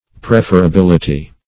Search Result for " preferability" : The Collaborative International Dictionary of English v.0.48: Preferability \Pref`er*a*bil"i*ty\, n. The quality or state of being preferable; preferableness.
preferability.mp3